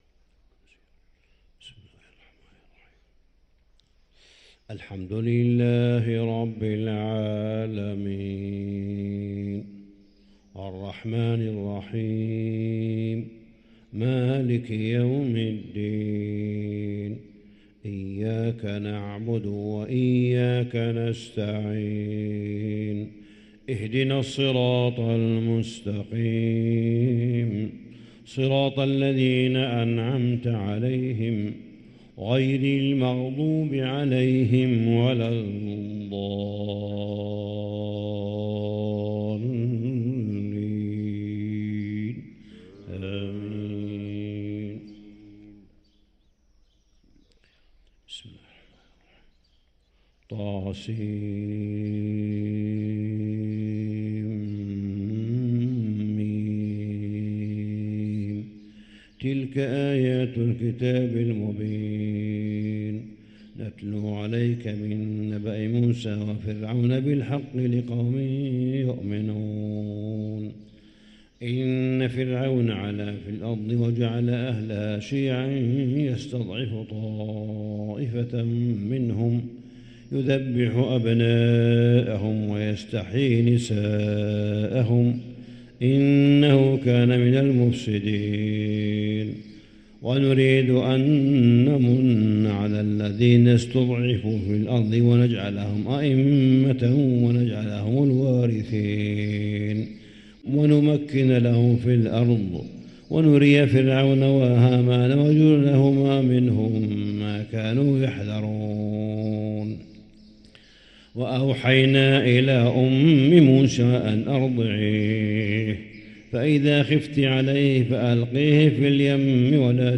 صلاة الفجر للقارئ صالح بن حميد 12 جمادي الآخر 1445 هـ
تِلَاوَات الْحَرَمَيْن .